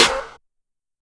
TM88 HurtSnare.wav